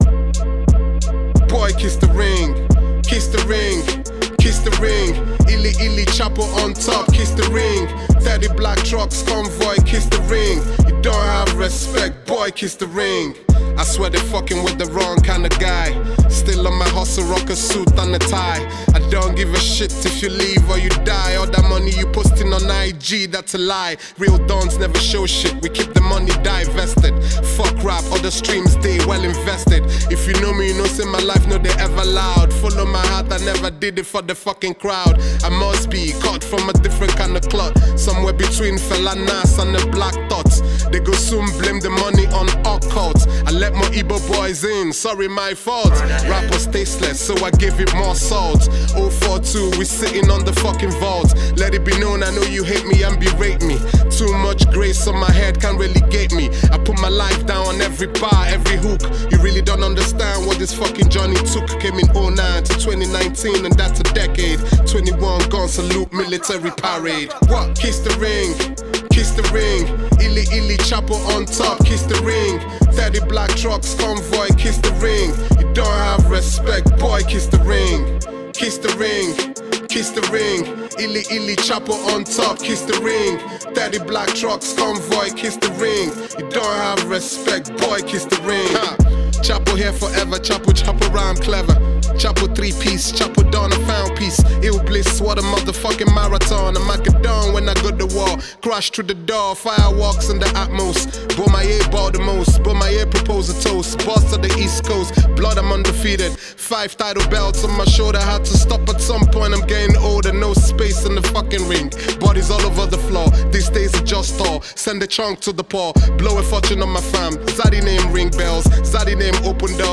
Nigerian rapper